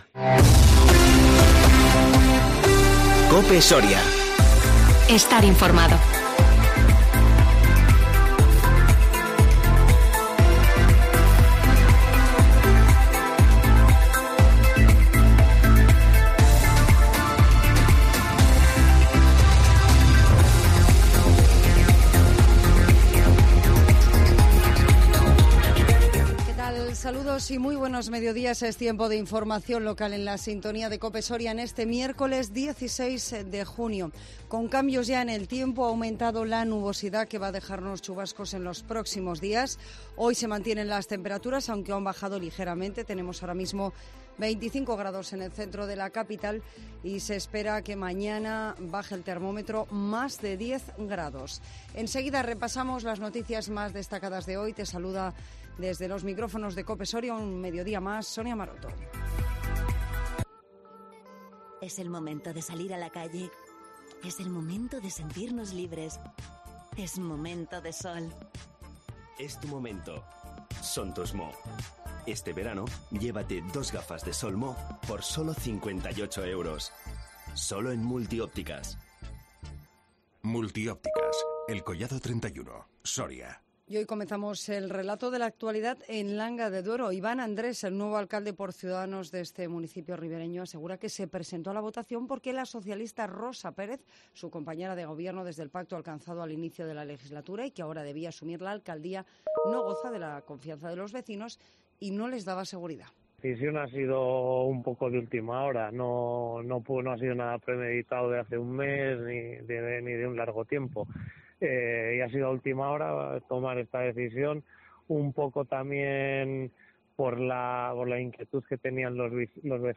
INFORMATIVO MEDIODIA 16 JUNIO 2021